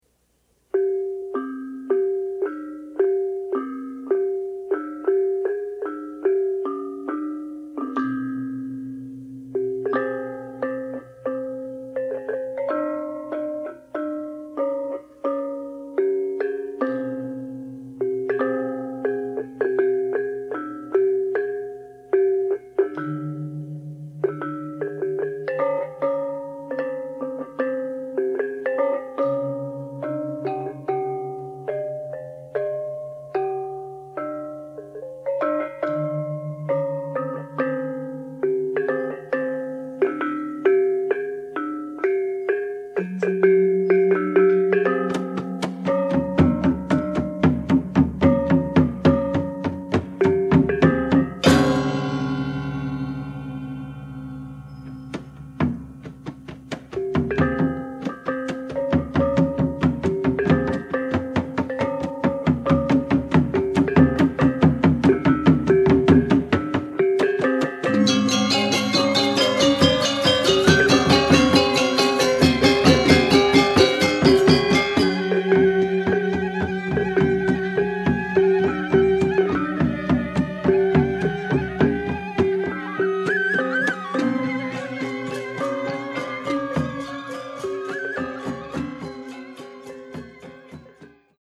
mp3はラジカセ(東芝 TY-CDX92)で作成しました。
mp3音源サンプル(A面冒頭)